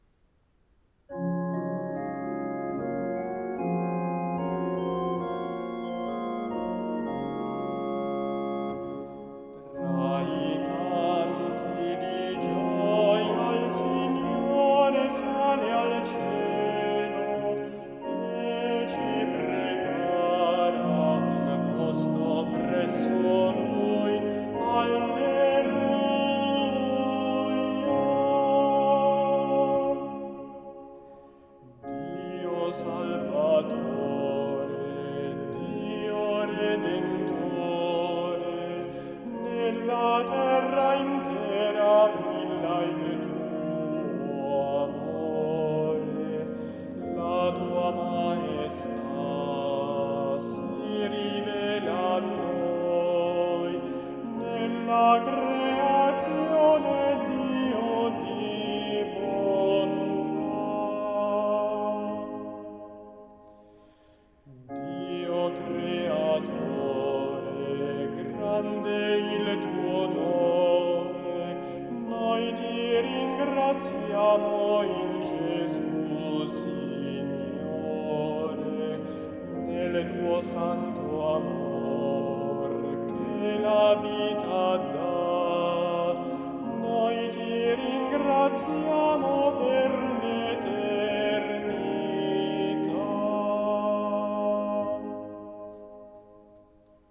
Audio esecuzione a cura degli animatori musicali del Duomo di Milano